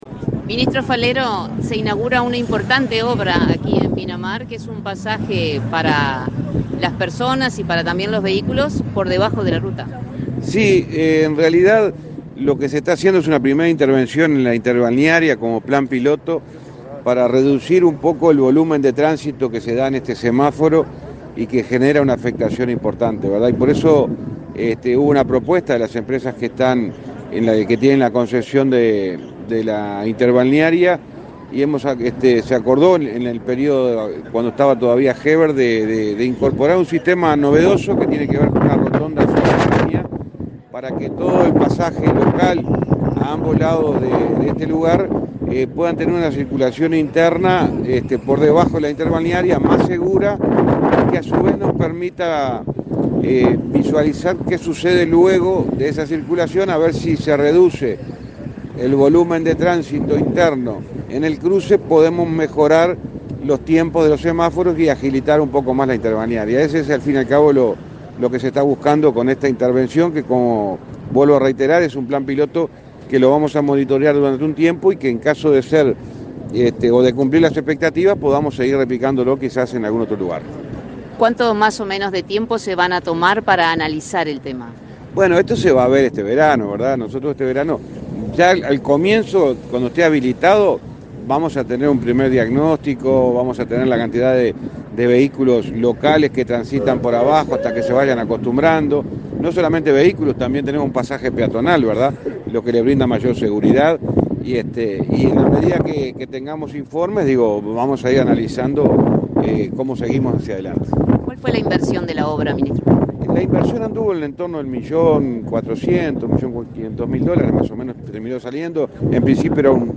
Entrevista al ministro de Transporte y Obras Públicas, José Luis Falero
Entrevista al ministro de Transporte y Obras Públicas, José Luis Falero 18/08/2022 Compartir Facebook X Copiar enlace WhatsApp LinkedIn El ministro de Transporte y Obras Públicas, José Luis Falero, participó, este 18 de agosto, en la inauguración del proyecto piloto que incluye túneles construidos en la ruta interbalnearia, en Pinamar, departamento de Canelones. En la oportunidad, el jerarca realizó declaraciones a Comunicación Presidencial.